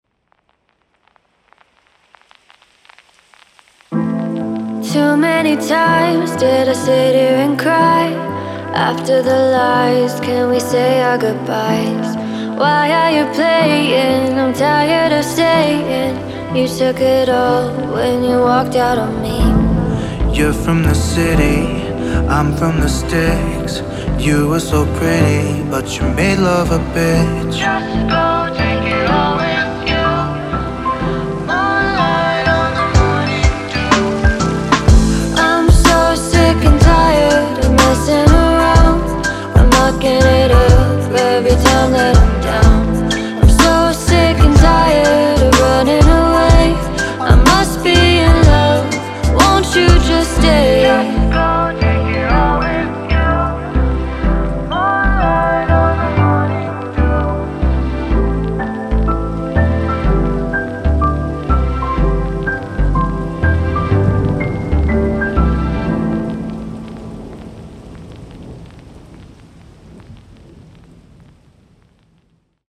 它包含来自7位专业歌手22种全无伴奏合唱，适用于各种音乐风格。